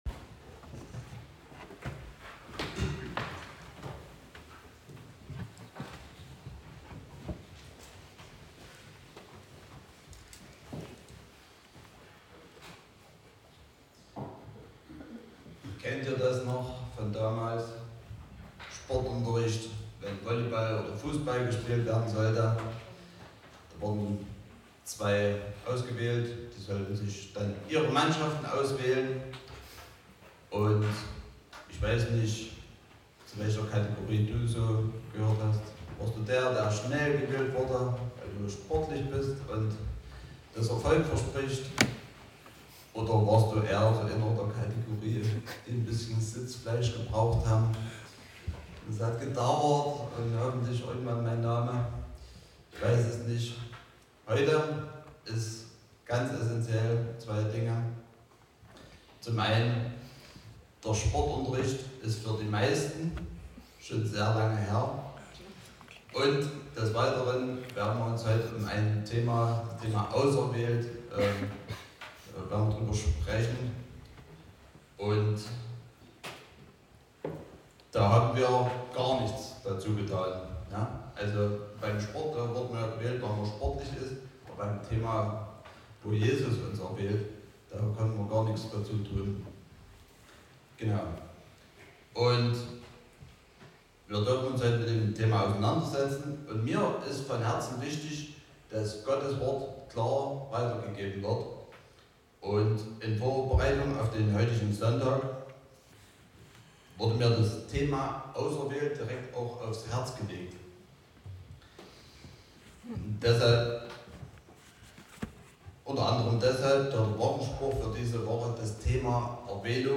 Passage: Johannes 15;16 Gottesdienstart: Predigtgottesdienst